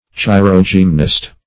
Search Result for " chirogymnast" : The Collaborative International Dictionary of English v.0.48: Chirogymnast \Chi`ro*gym"nast\, n. [Gr. chei`r hand + ? trainer of athletes, gymnast.]